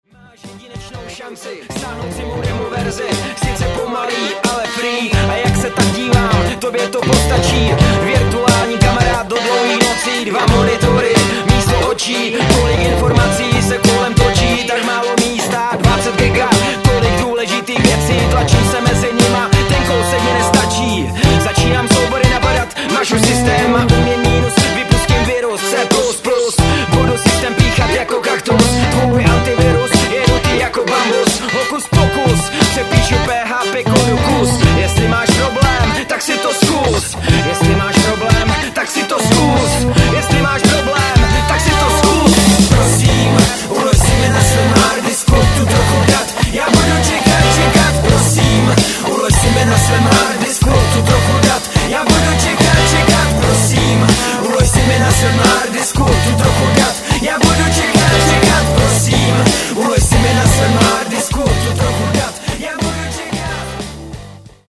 bluesrap band